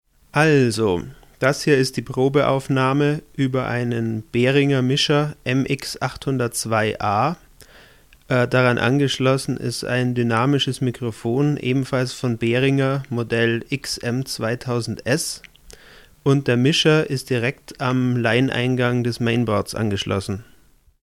Insbesondere ist da selbst beim Line-Eingang ein Surren drin, dessen Ursache ich auf die Schnelle nicht gefunden habe.
Dennoch hier eine kurze Sprach-Testaufnahme.
Z. B. die besagten Surrgeräusche in der verlinkten Tonaufnahme höre ich auf meinen PC-Lautsprecher nicht, im Kopfhörer aber sehr deutlich.
mikro-test.mp3